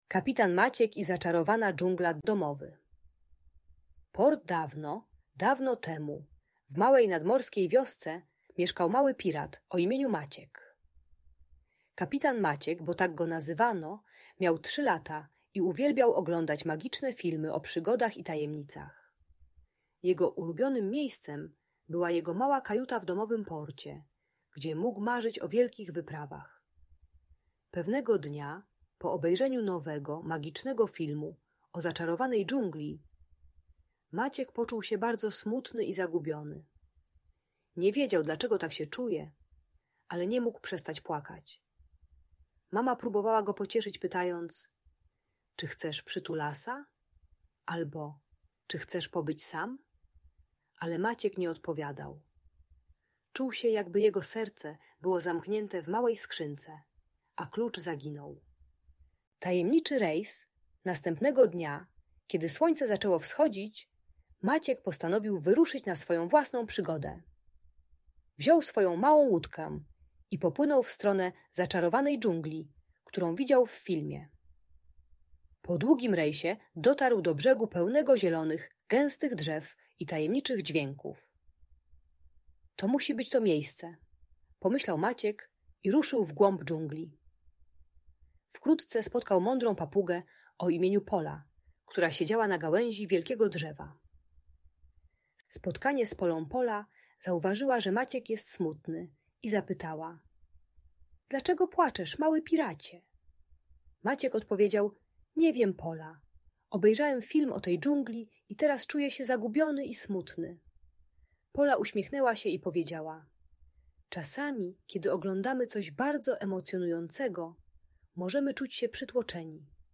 Przygoda Kapitana Maćka w Zaczarowanej Dżungli - Bajka - Audiobajka dla dzieci